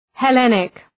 {he’lenık}